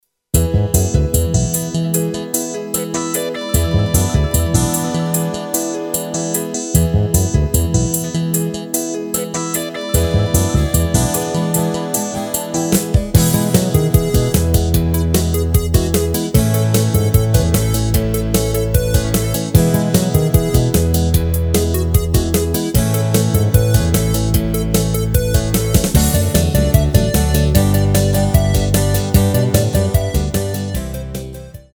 Rubrika: Folk, Country